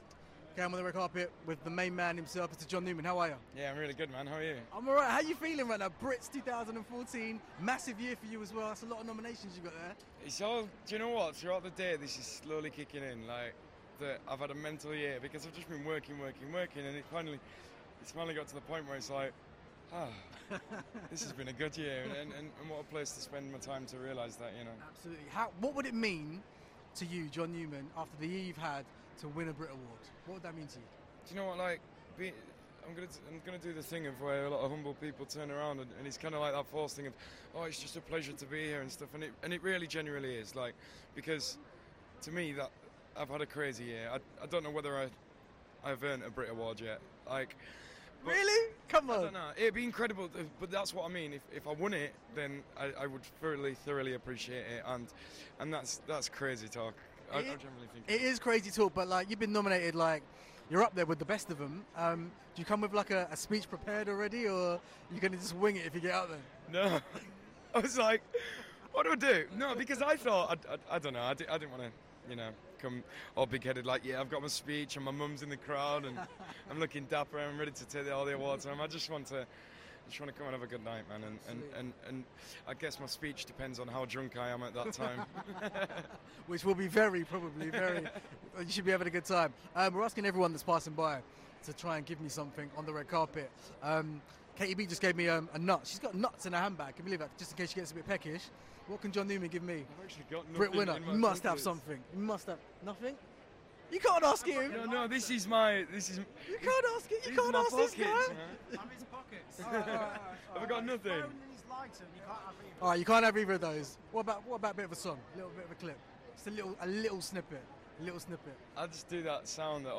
John Newman on the red carpet at the Brits!